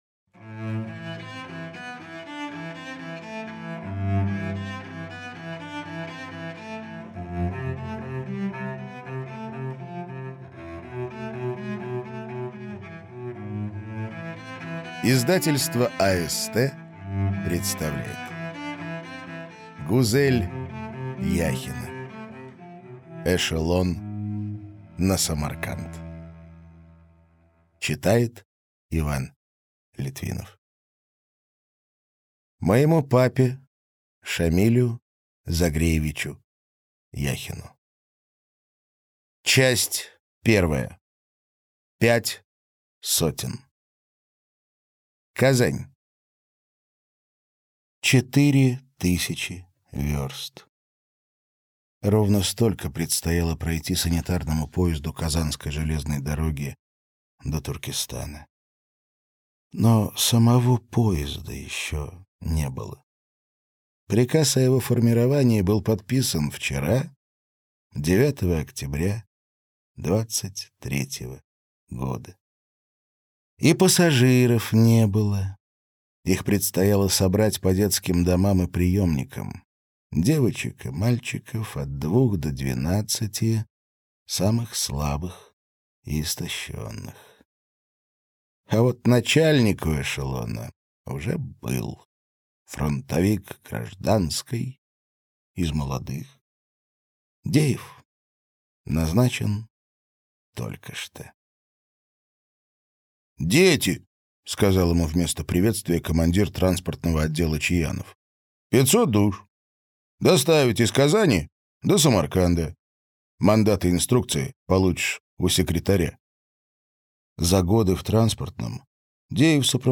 Аудиокнига Эшелон на Самарканд - купить, скачать и слушать онлайн | КнигоПоиск